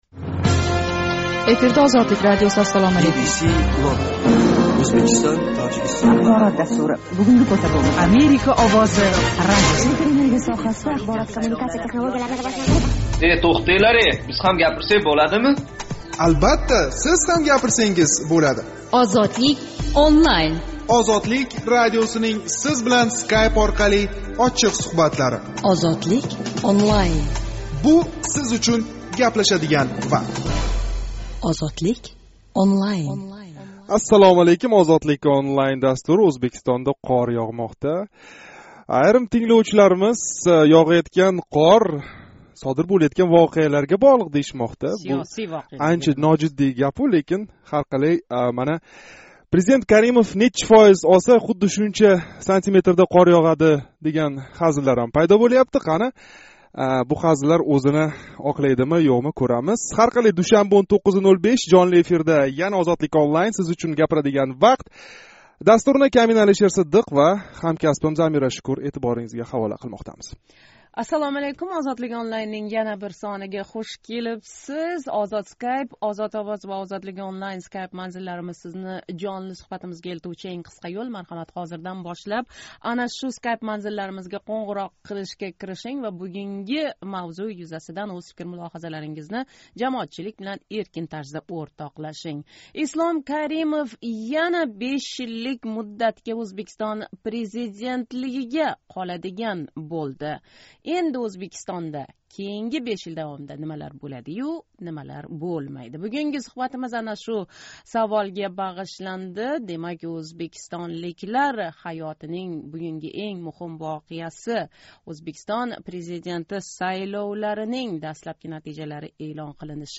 МСК президент сайлови натижасини эълон қилмай туриб¸ ўзбекистонликлар Каримовни президентликда яна қолганини қабул қилиб бўлишди. 25 йилдан бери Ўзбекистонни ўзидан бошқага бермай келаëтган Каримовнинг ҳокимиятда қолиши Сиз учун нимани англатади? Душанба кунги жонли суҳбатда шу ҳақда гаплашамиз.